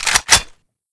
boltpull.wav